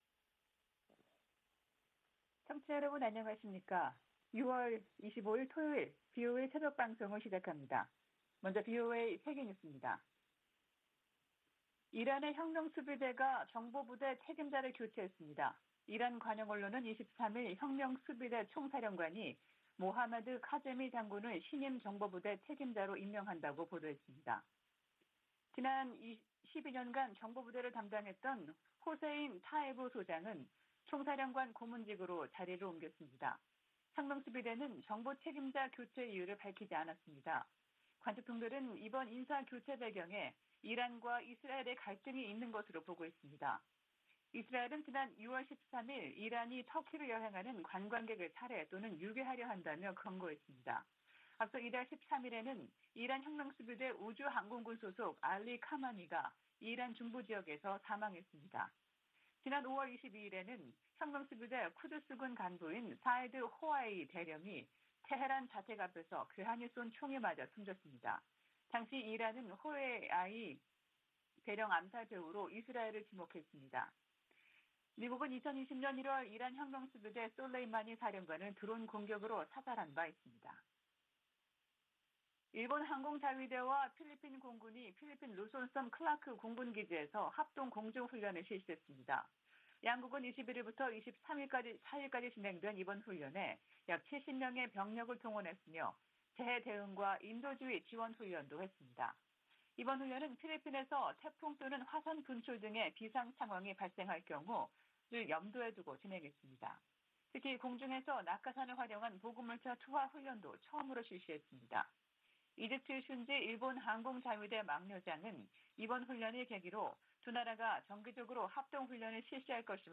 VOA 한국어 '출발 뉴스 쇼', 2022년 6월 25일 방송입니다. 미 하원 군사위 의결 국방수권법안(NDAA)에 한국에 대한 미국의 확장억제 실행 방안 구체화를 요구하는 수정안이 포함됐습니다. 미국의 군사 전문가들은 북한이 최전선에 전술핵을 배치한다고 해도 정치적 의미가 클 것이라고 지적했습니다. 북한은 노동당 중앙군사위원회 확대회의에서 전쟁억제력 강화를 위한 중대 문제를 심의 승인했다고 밝혔습니다.